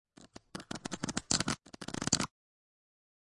50个声音库 " 卡牌洗牌法
描述：正在洗牌的扑克牌
标签： 卡甲板 洗牌卡
声道立体声